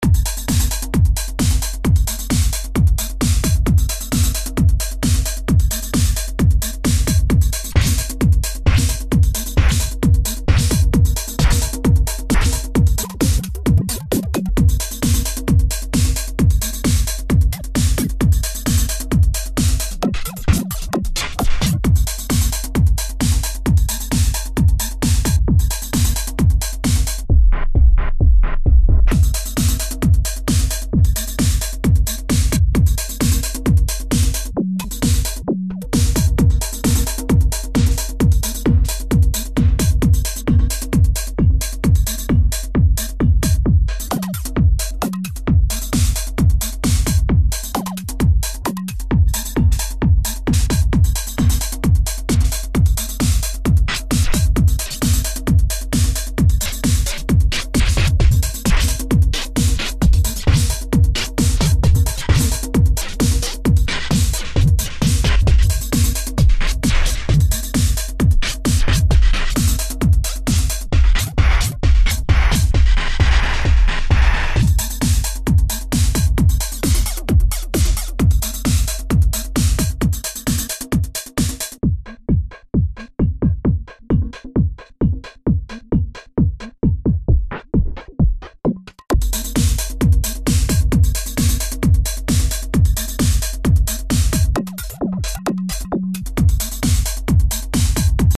Techno Heads… download this.
It’s a perfectly timed effect that NEVER goes out of Sync / time because of the way I have pulled this effect off.
I can't tell you how much I love this effect on the techno one!
techno-bends.mp3